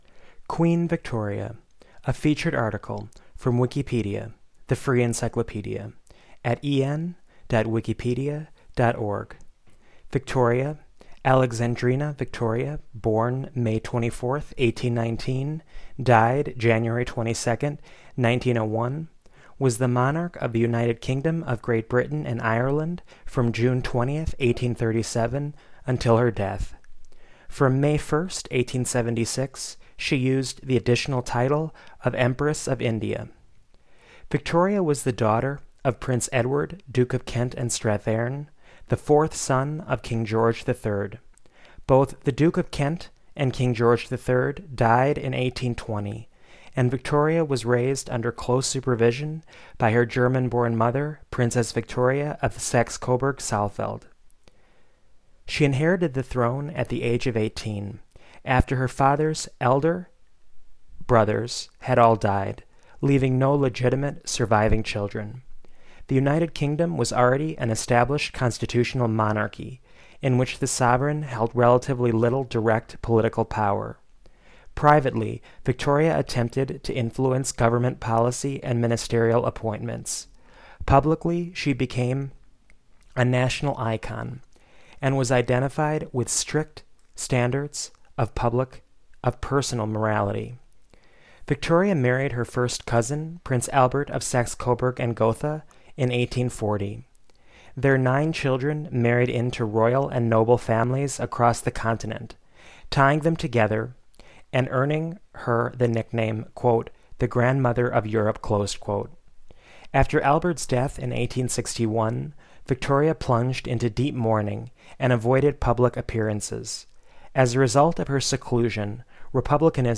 Spoken Version of English Language Wikipedia Article on Queen Victoria